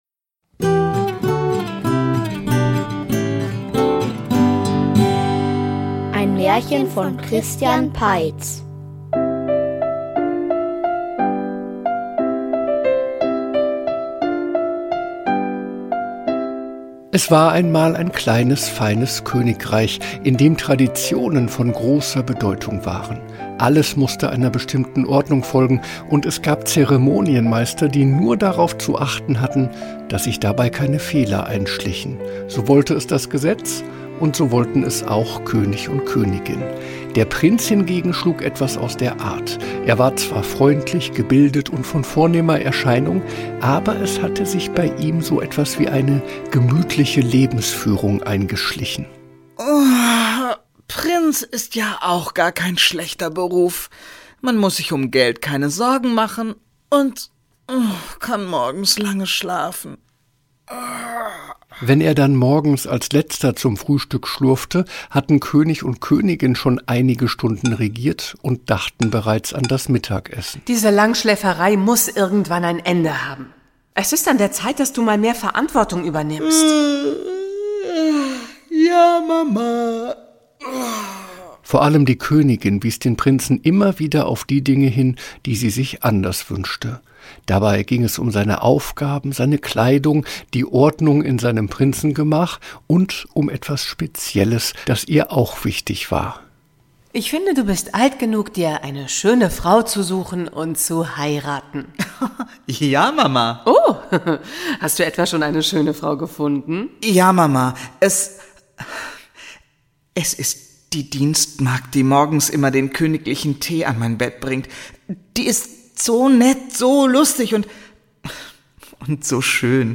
Die Teppichprinzessin --- Märchenhörspiel #49 ~ Märchen-Hörspiele Podcast